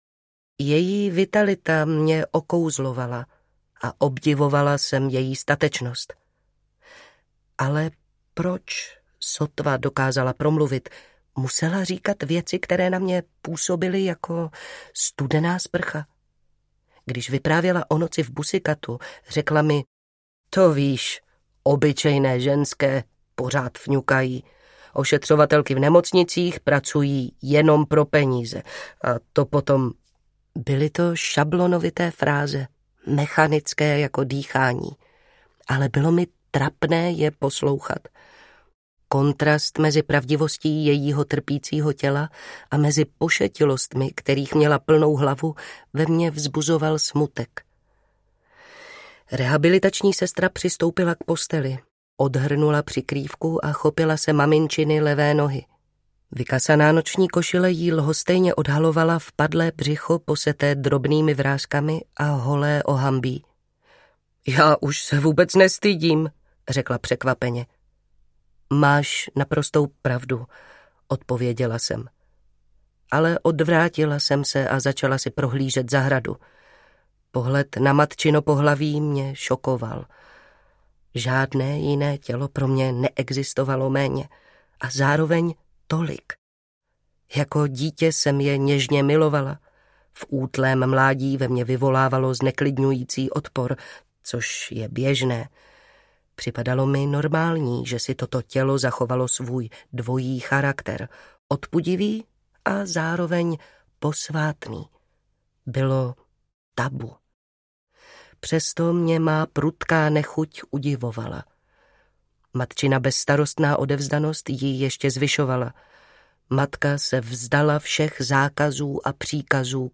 Velice lehká smrt audiokniha
Ukázka z knihy
Vyrobilo studio Soundguru.